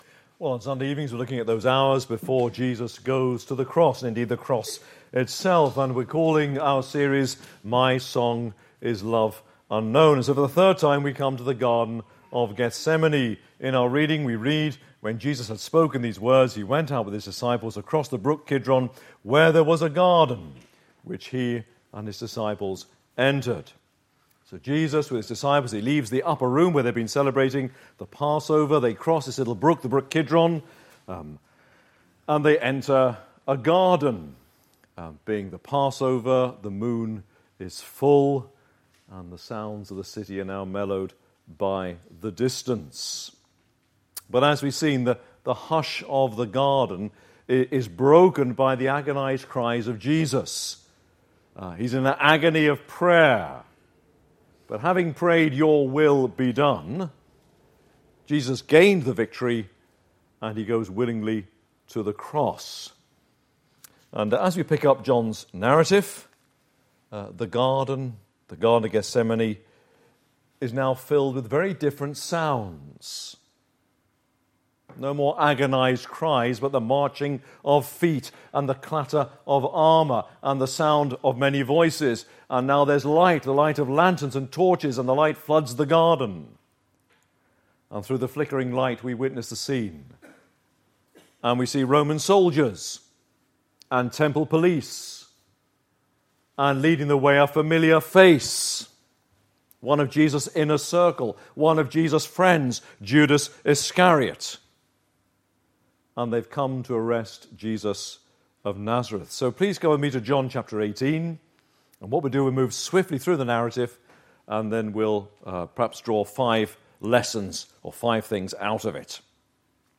APC - Sermons